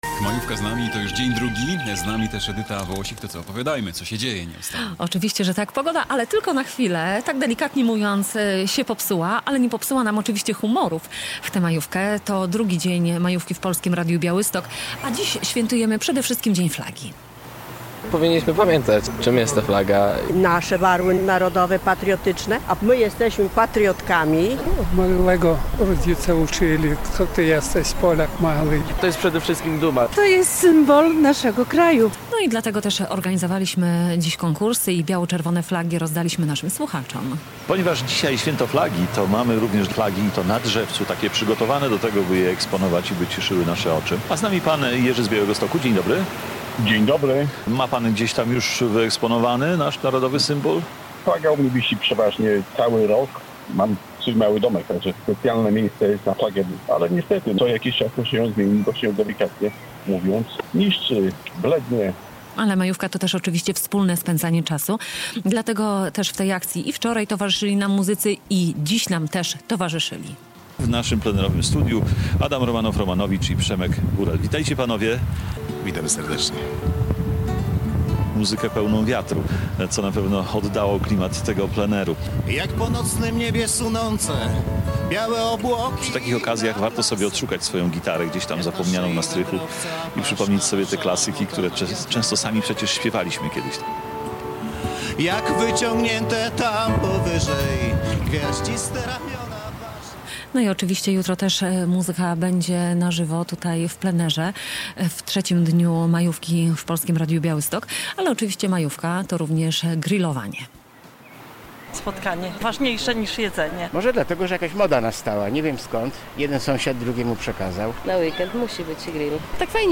We wtorek (02.05) - podobnie jak wczoraj - nasz program nadawaliśmy z plenerowego studia przy ul. Świerkowej 1.